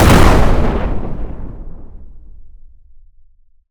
explosion_large_08.wav